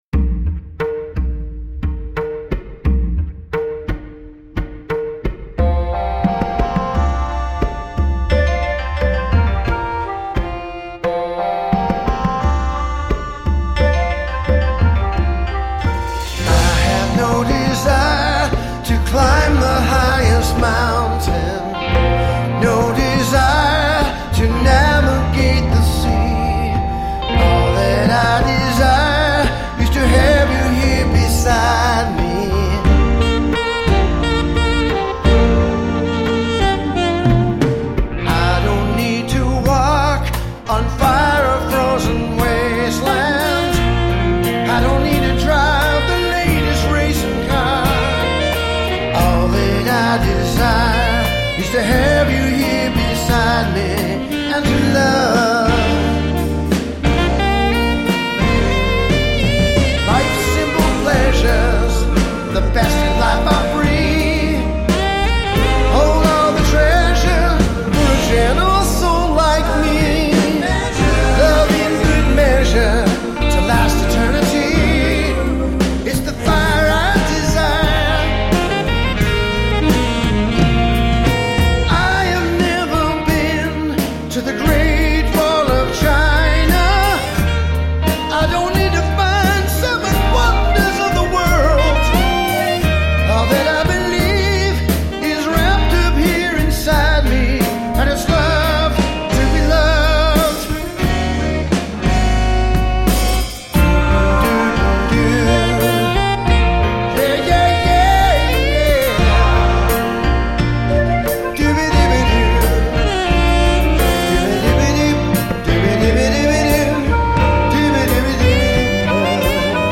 alto sax